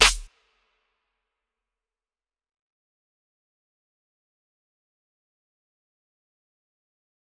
DMV3_Clap 7.wav